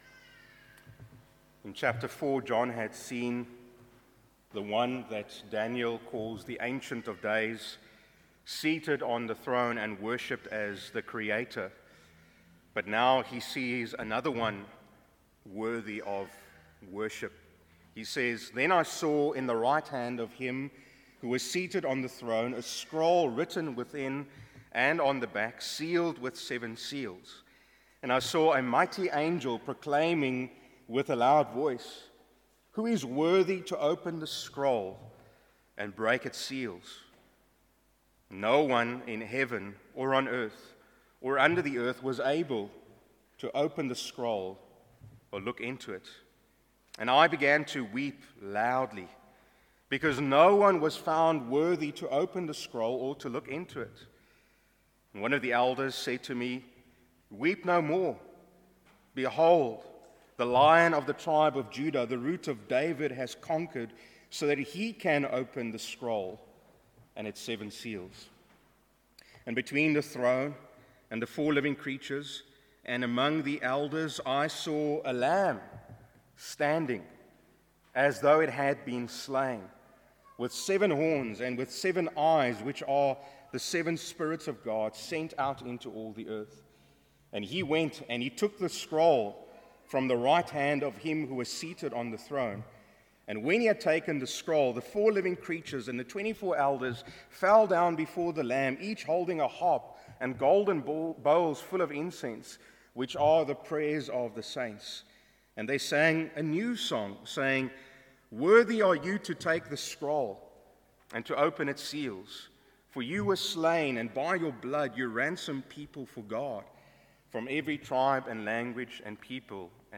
Sermons under misc. are not part of a specific expositional or topical series.